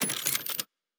Weapon 06 Reload 1.wav